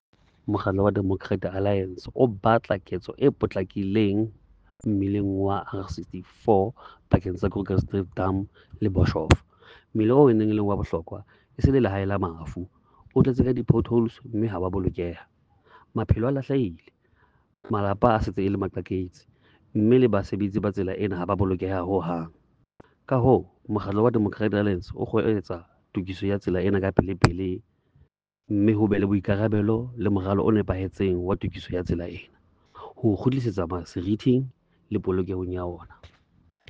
Sesotho soundbites by Cllr Ernest Putsoenyane.
R64-becomes-a-deadly-road-SOTHO-.mp3